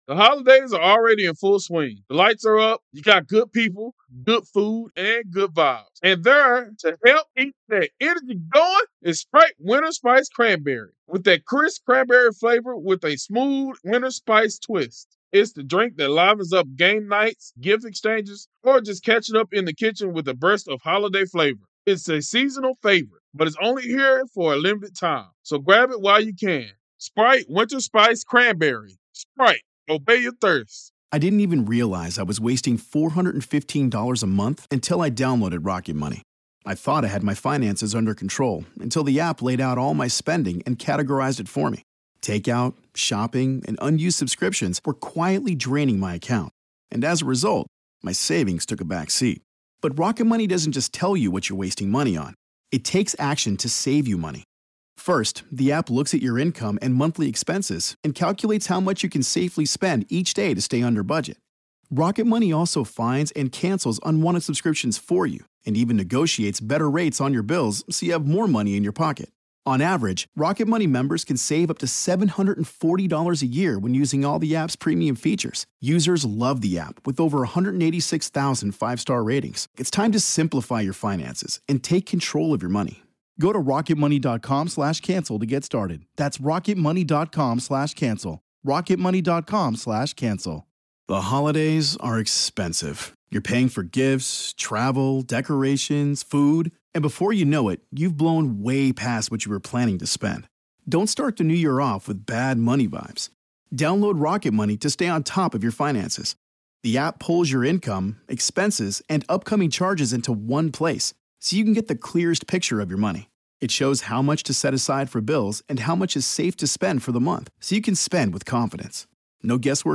Interview Part 1